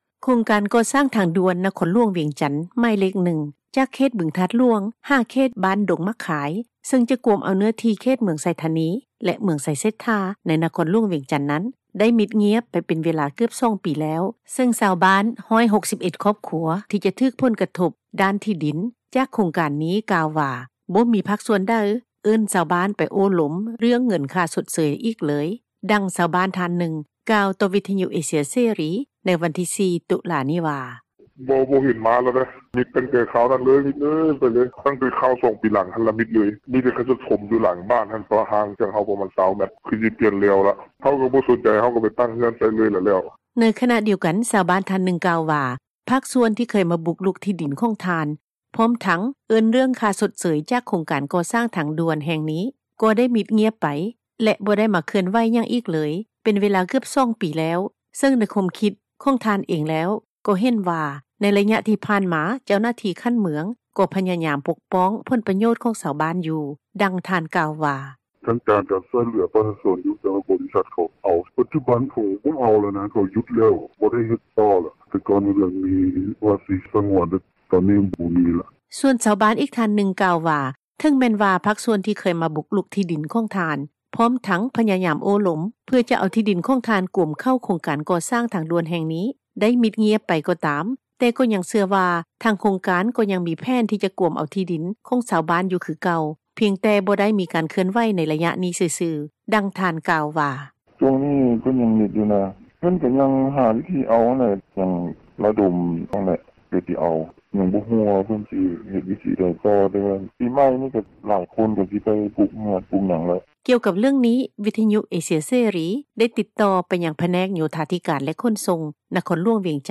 ດັ່ງ ຊາວບ້ານທ່ານນຶ່ງ ກ່າວຕໍ່ວິທຍຸ ເອເຊັຽເສຣີ ໃນວັນທີ 4 ຕຸລານີ້ວ່າ:
ດັ່ງຜູ້ປະກອບການ ຂັບຣົຖແທັກຊີ້ ທ່ານນຶ່ງກ່າວວ່າ: